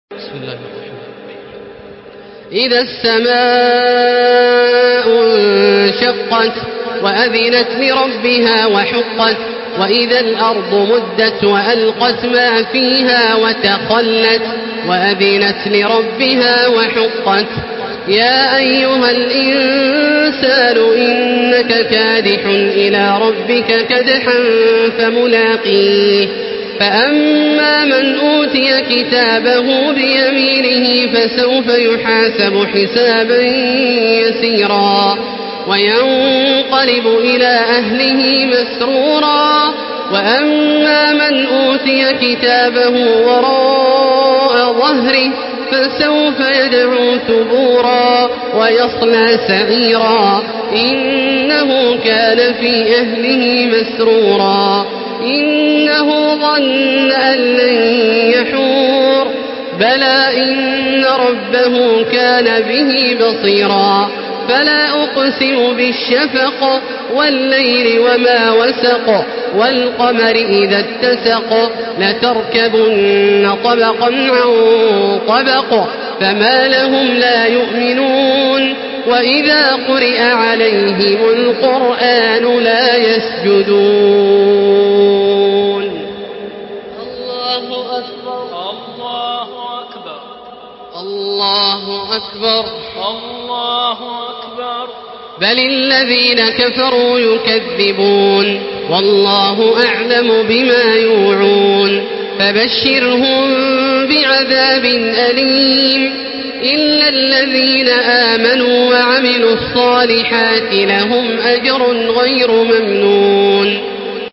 Surah Al-Inshiqaq MP3 by Makkah Taraweeh 1435 in Hafs An Asim narration.
Murattal Hafs An Asim